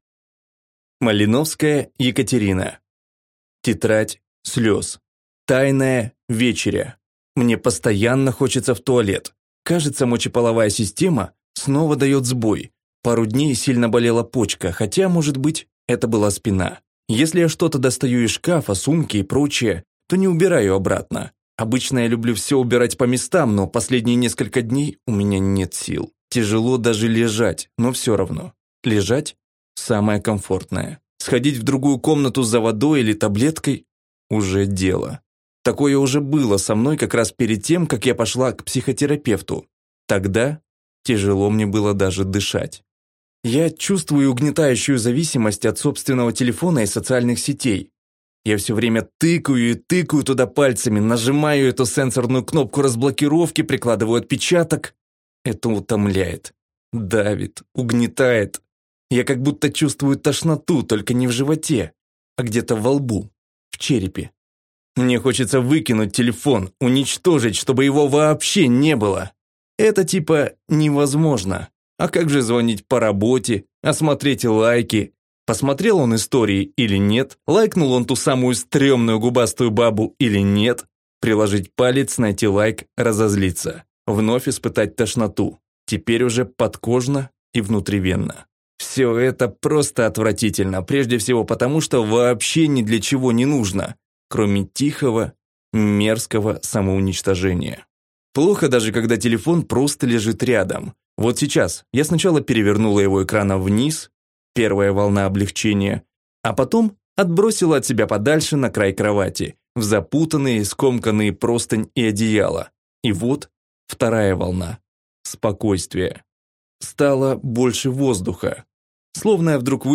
Аудиокнига Тетрадь слёз | Библиотека аудиокниг